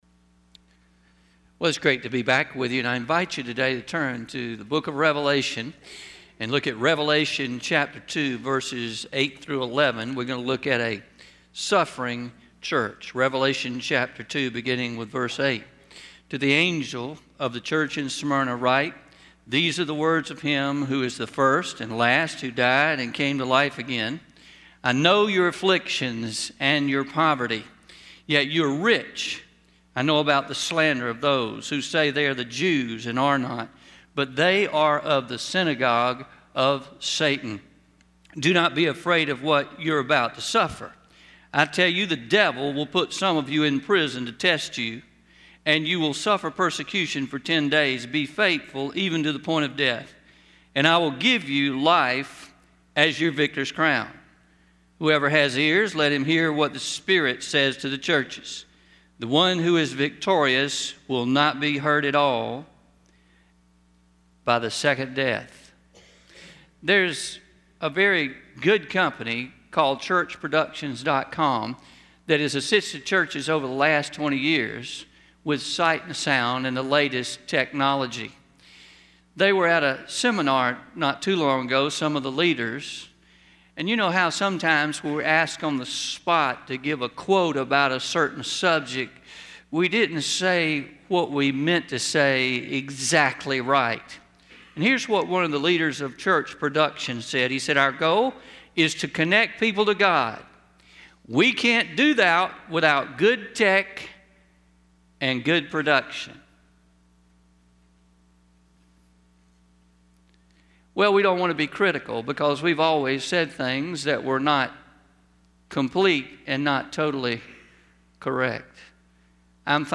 Traditional-09-05-21am Sermon – A Suffering Church